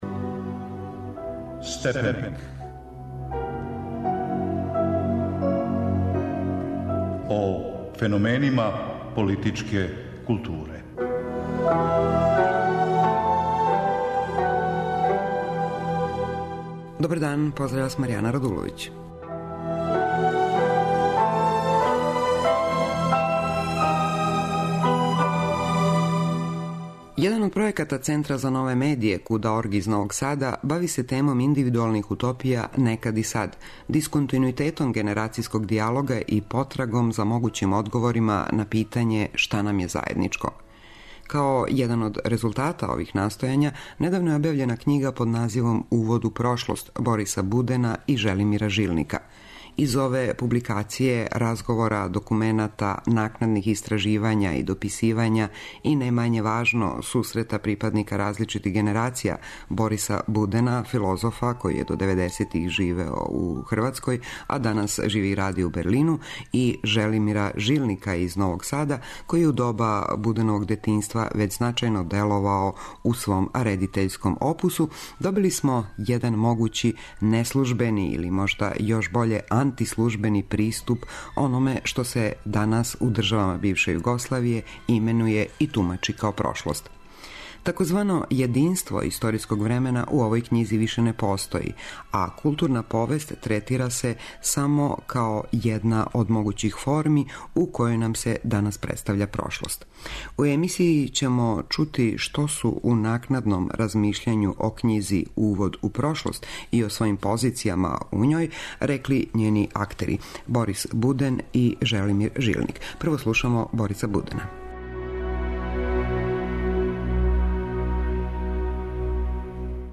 "Увод у прошлост" није књига о прошлости, она је увод у проблем зван прошлост. За емисију Степеник , говоре Борис Буден и Желимир Жилник.